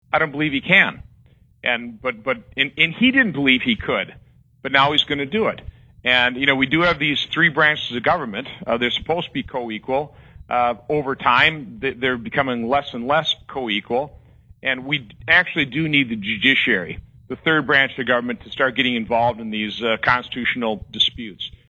Senator Johnson gave these answers during an interview on Thursday, Nov. 20, with WSAU News/Talk AM 550.